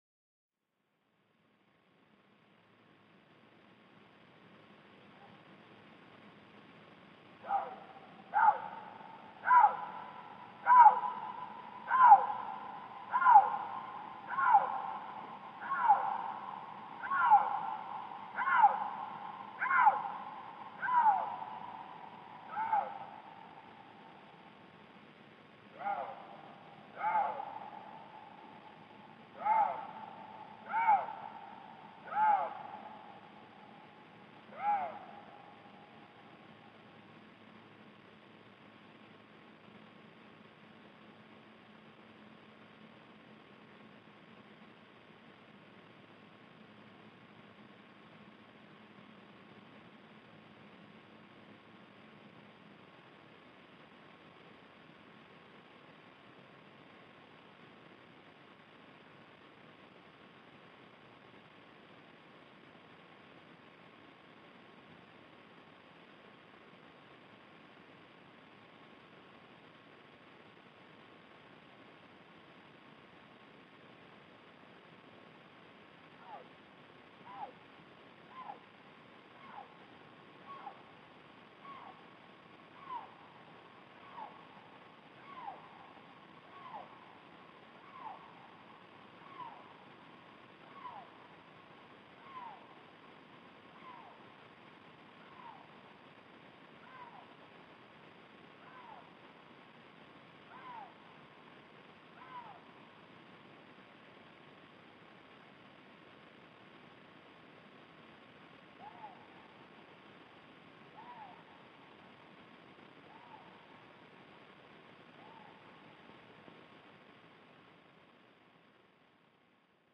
Nisäkkäiden ääniä, 2. osa: Ilves
Tässä 9-osaisessa sarjassa tutustumme nisäkkäiden ääniin. Toisena vuorossa on ilves.